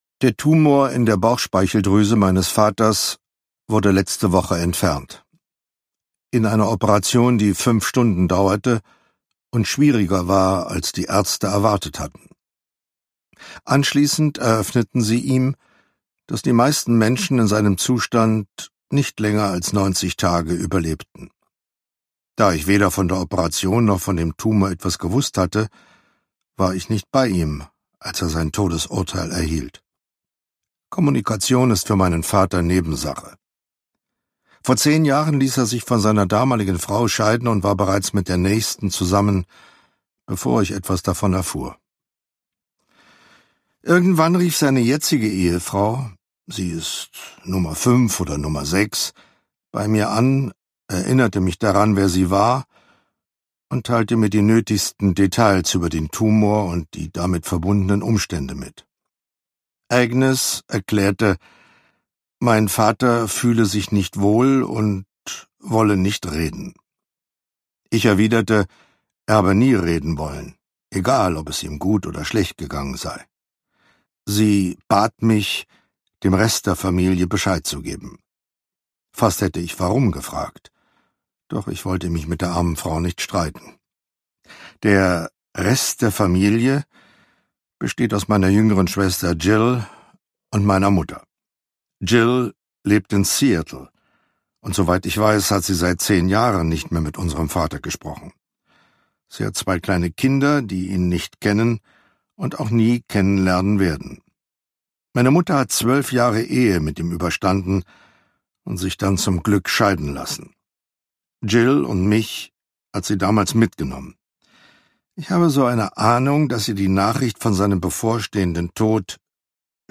Home Run ungekürzte Lesung